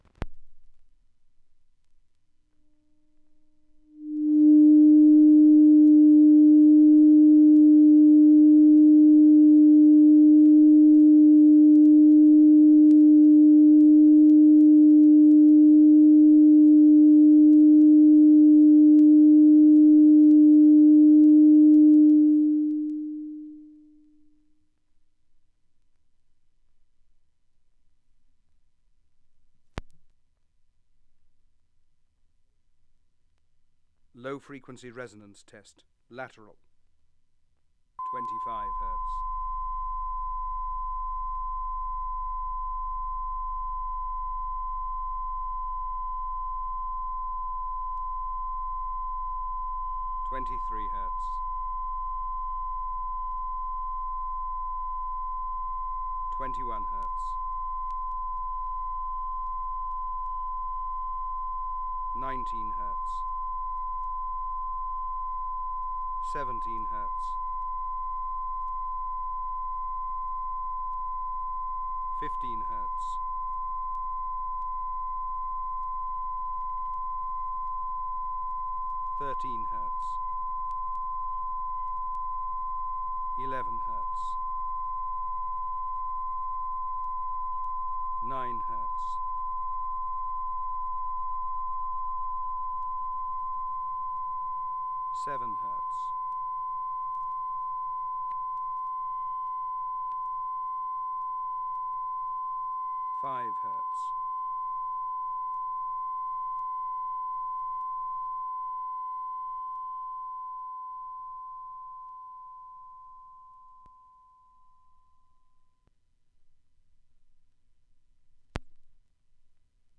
ATP-2 Moving Magnet Cartridge
S/N: >50dB
LP Hi-Fi News (HFN002) Test LP, Side B
It started mistracking at +16dB.
Hi-Fi_News-Side_B_(Audio-Technica-AT-LP120-USB_Turntable).flac